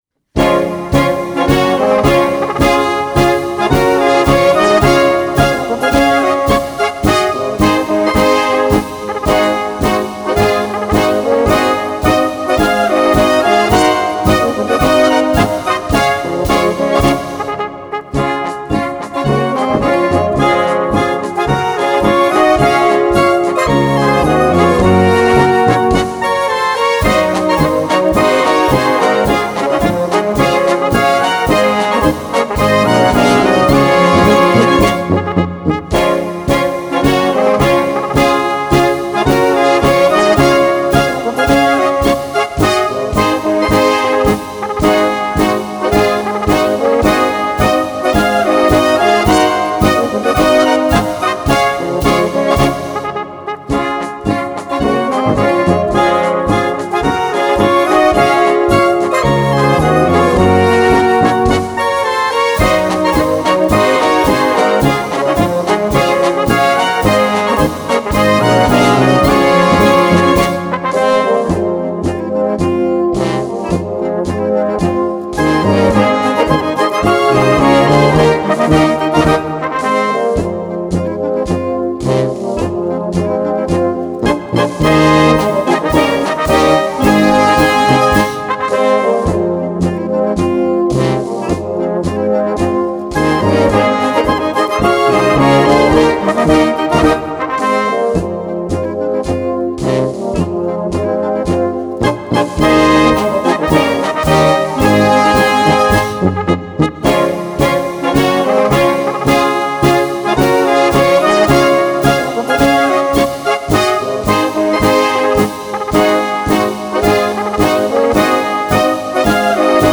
Märsche für Blasmusik